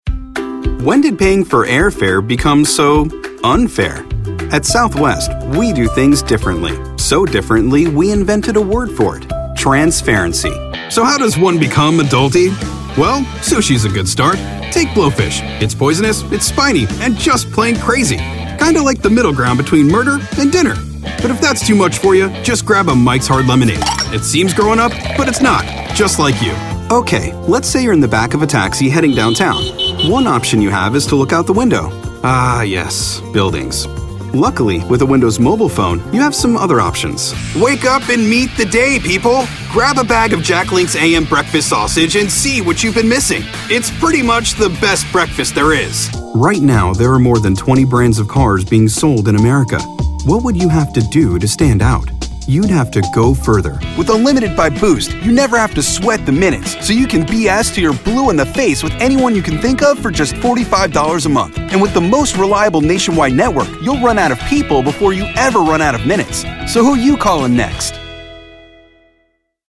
Mature Adult, Adult, Young Adult
Has Own Studio
standard us
commercial